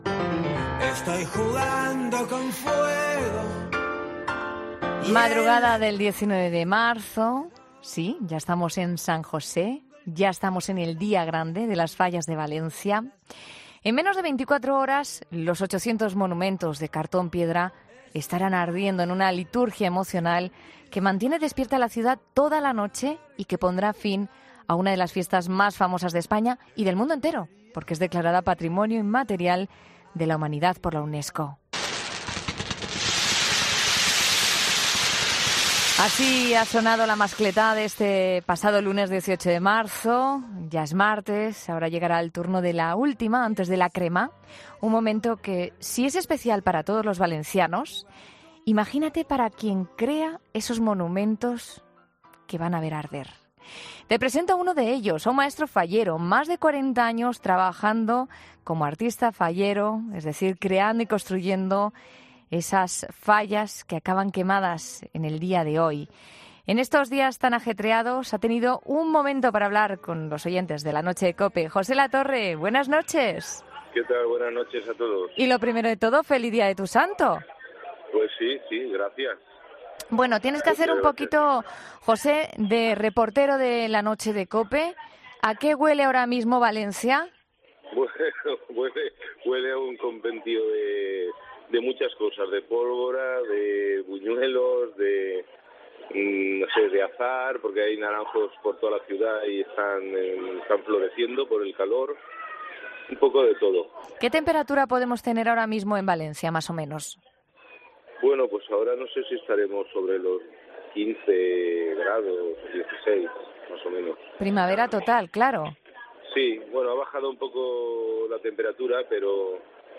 En 'La Noche de COPE' hemos hablado con uno de ellos.